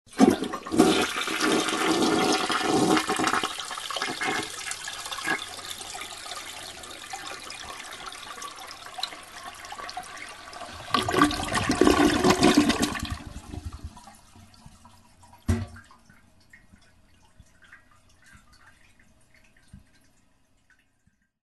Звуки поноса
Звуки мужчины с жидким стулом в туалете